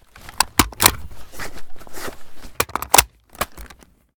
sv98_reload.ogg